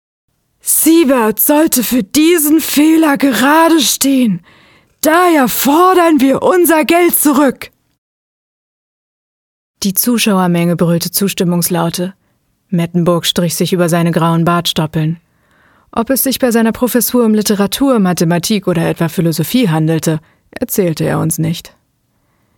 Weltkrieg Hörbuch Seabirds - Marktbesuch Seabirds - Prof. Mettenburg Radiofeature Die Haus-WG mit Kindern Doku-Kommentar Die komplette Folge findet ihr hier .
Hoerbuch-Seabirds_-Prof-Mettenburg.mp3